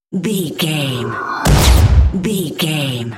Airy whoosh explosion hit
Sound Effects
dark
tension
woosh to hit